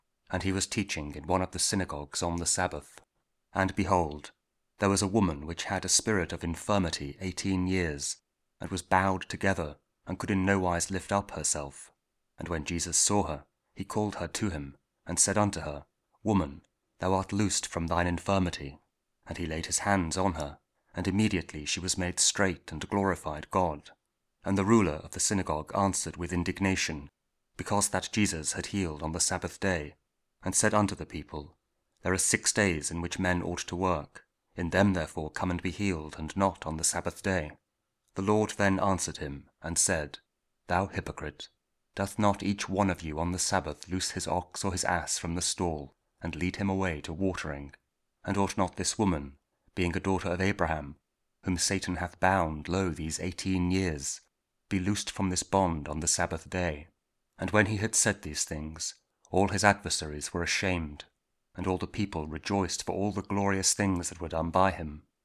Luke 13: 10-17 – Week 30 Ordinary Time, Monday (King James Audio Bible KJV, Spoken Word)